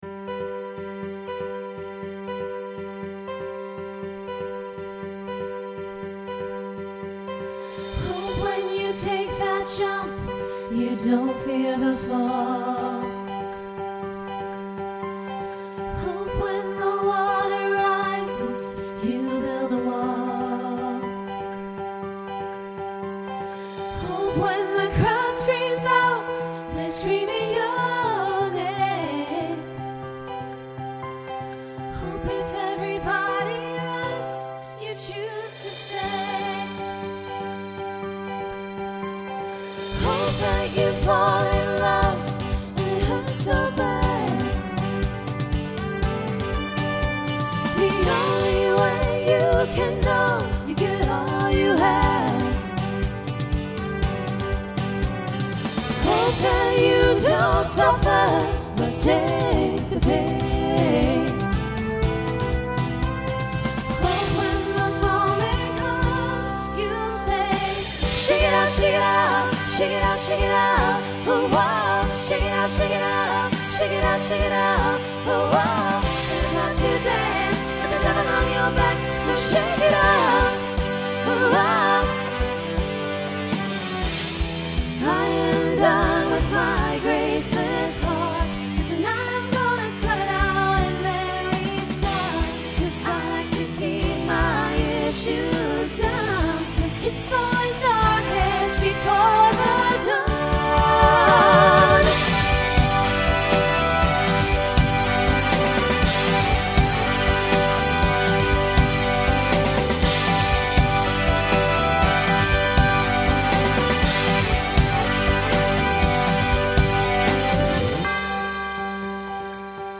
Voicing SSA Instrumental combo Genre Pop/Dance
Mid-tempo